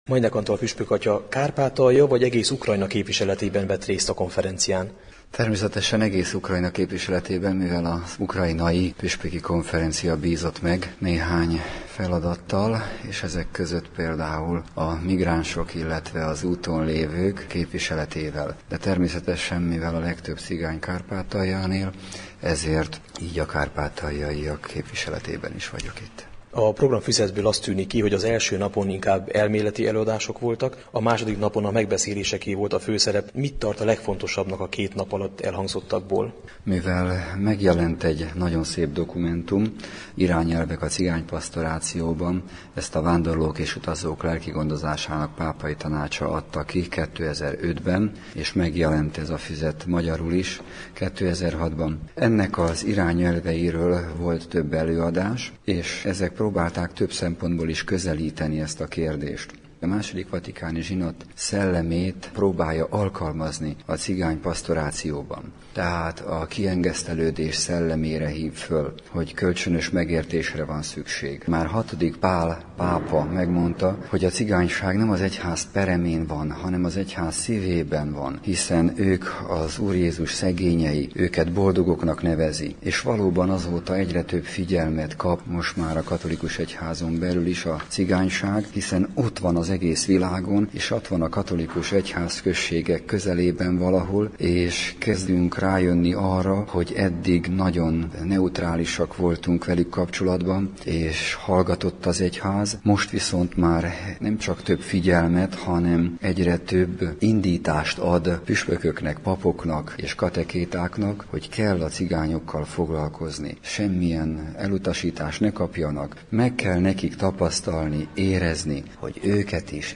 Velük készült beszélgetésünk a konferencián szerzett tapasztalataikról, benyomásaikról.